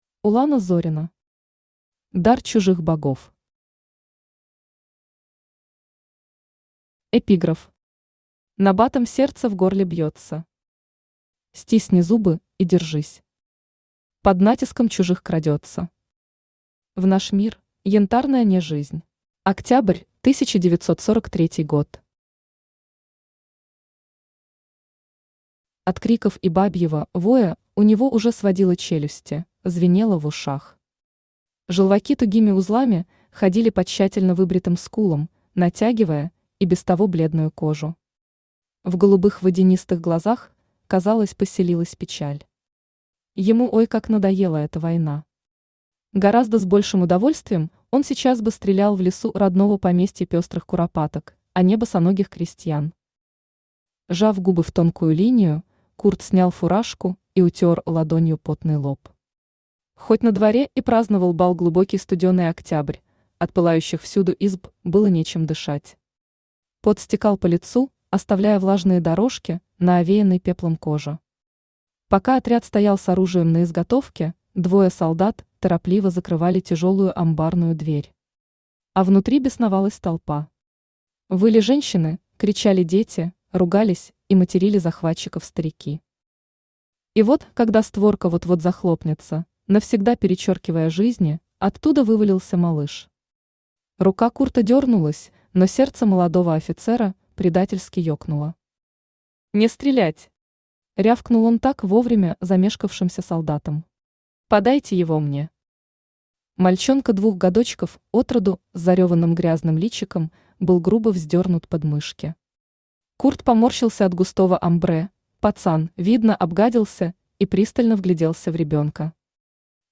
Аудиокнига Дар чужих Богов | Библиотека аудиокниг
Aудиокнига Дар чужих Богов Автор Улана Зорина Читает аудиокнигу Авточтец ЛитРес.